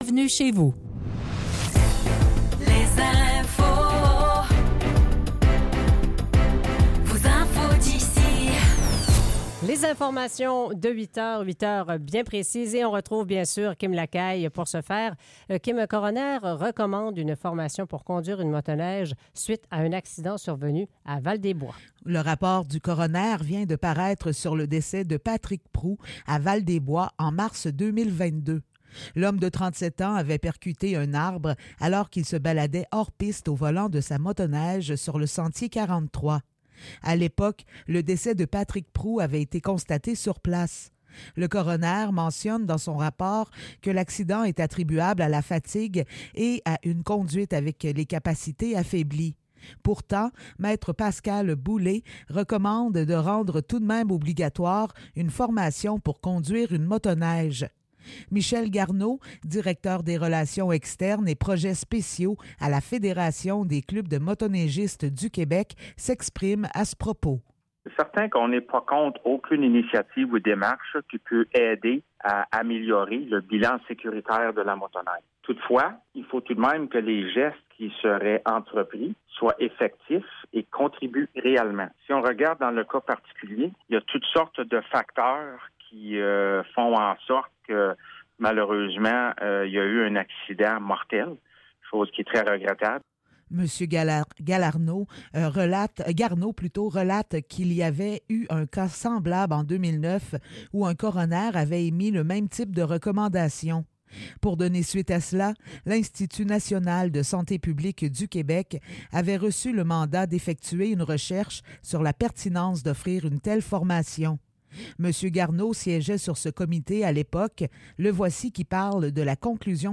Nouvelles locales - 22 février 2024 - 8 h